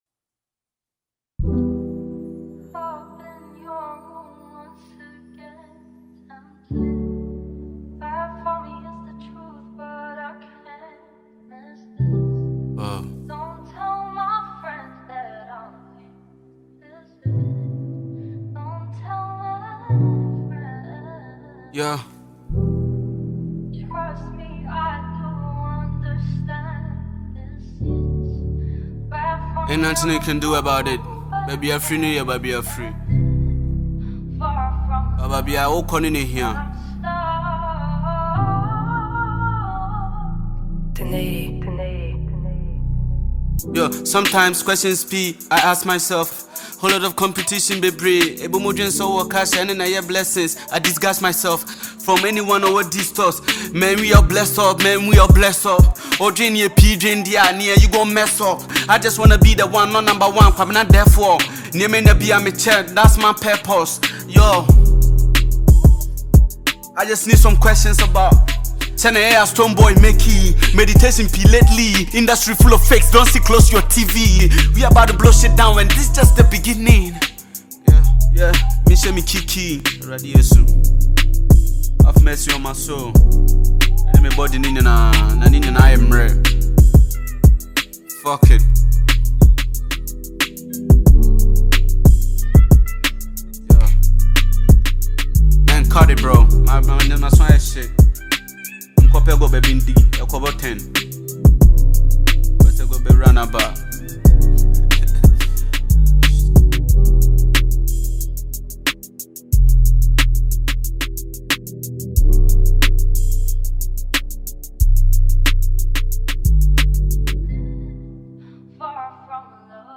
Sensational Ghanaian trapper